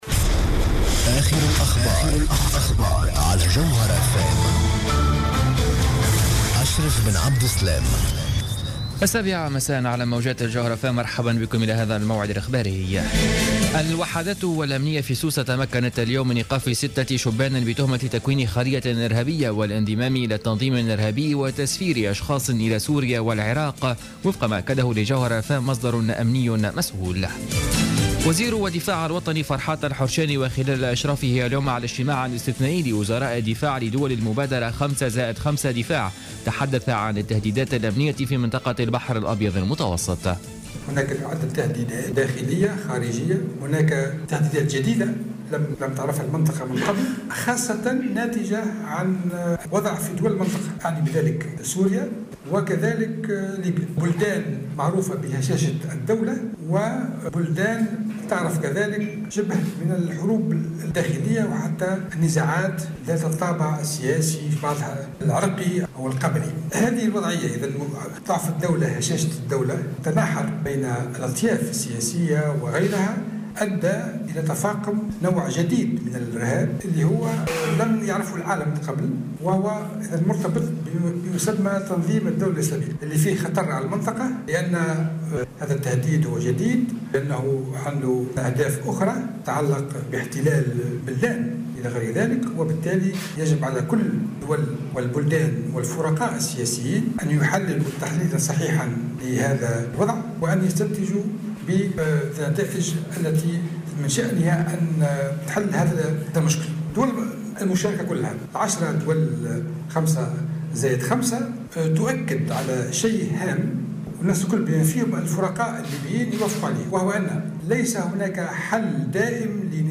نشرة أخبار السابعة مساء ليوم الثلاثاء 09 جوان 2015